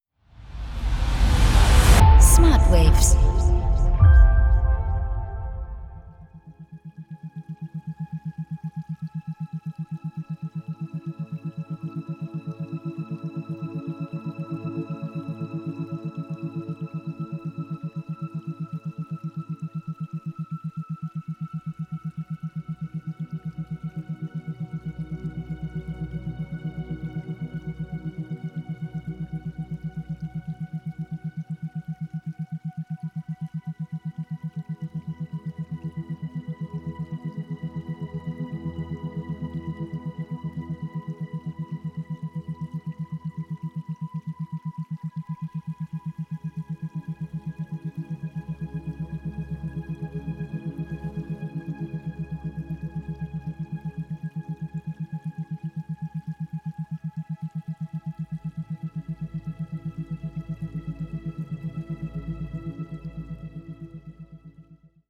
• Methode: Isochrone Beats
• Frequenz: 7,83 Hertz